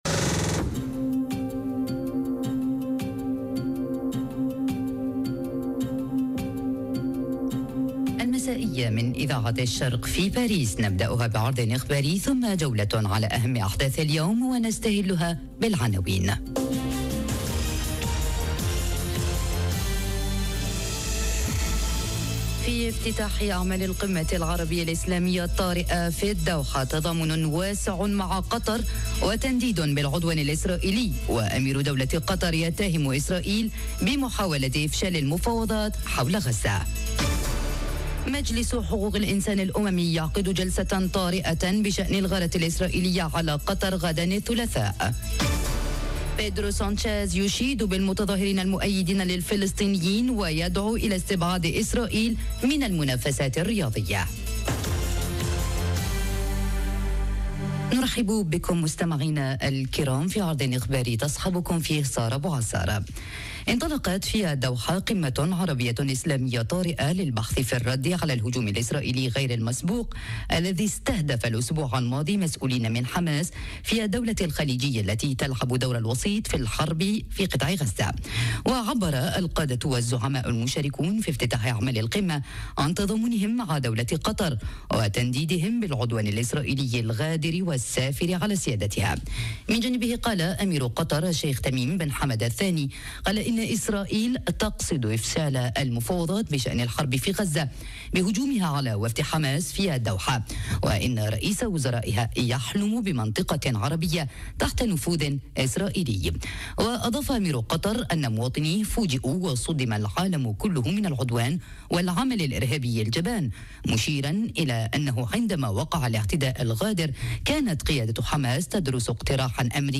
نشرة أخبار المساء : القمة الطارئة في الدوحة وتداعيات الغارة الإسرائيلية على قطر تتصدر المشهد العربي والدولي - Radio ORIENT، إذاعة الشرق من باريس